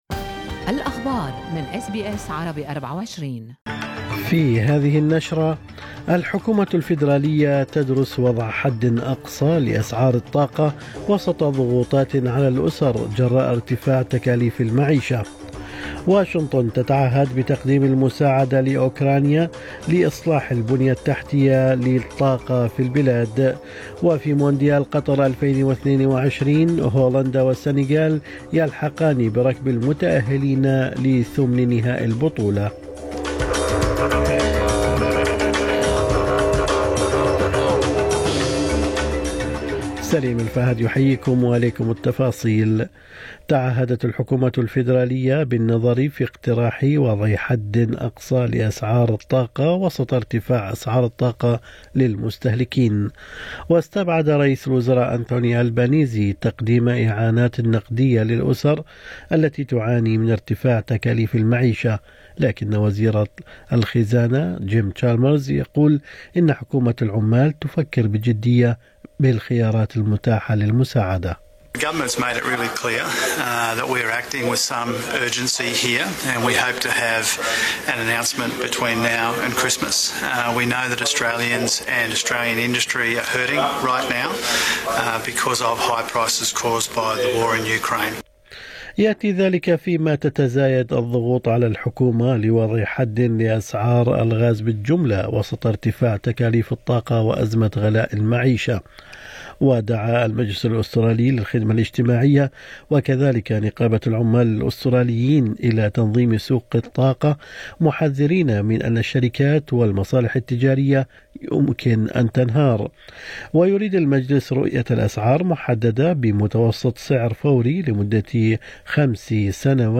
نشرة أخبار الصباح 30/11/2022